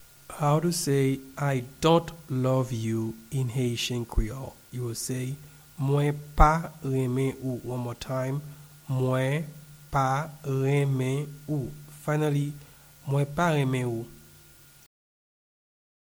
Pronunciation and Transcript:
I-dont-love-you-in-Haitian-Creole-Mwen-pa-renmen-ou-pronunciation.mp3